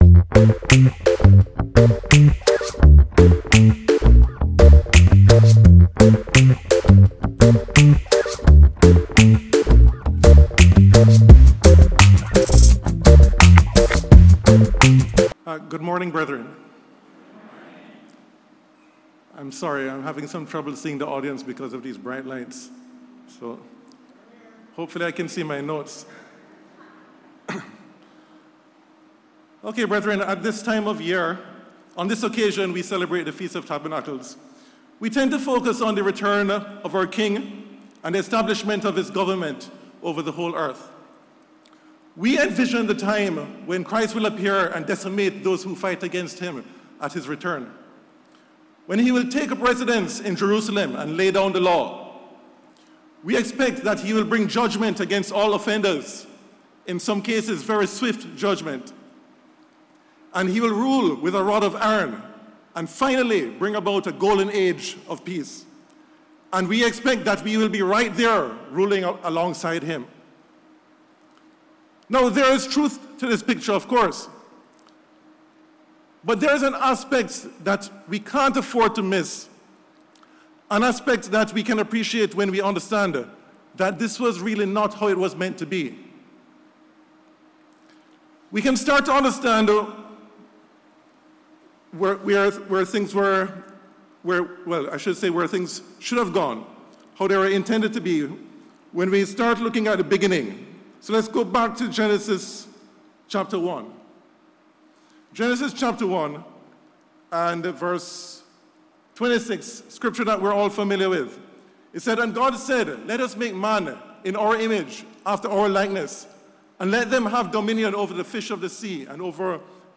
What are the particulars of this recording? This sermon was given at the Montego Bay, Jamaica 2021 Feast site.